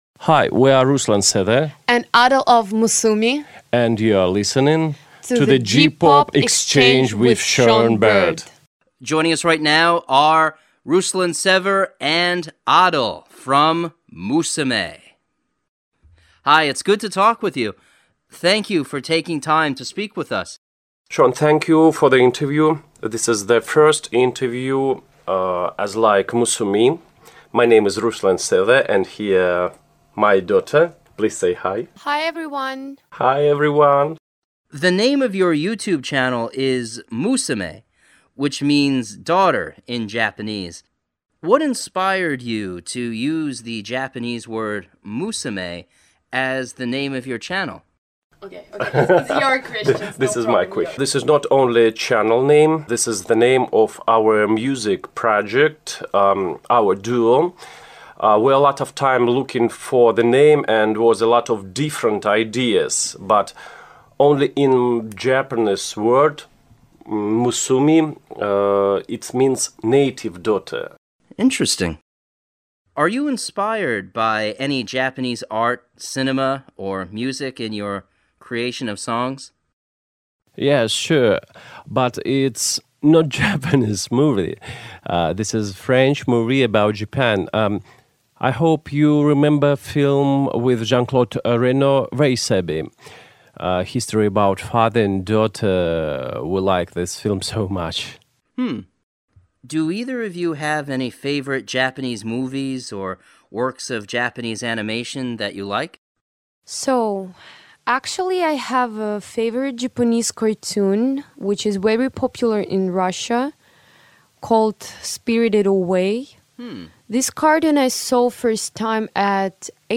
Radio Interview
Masume_Radio_Interview.mp3